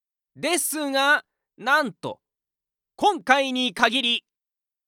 パロディ系ボイス素材　5
元ネタが何かしらの作品中に含まれているor作品にまつわるタイプの声素材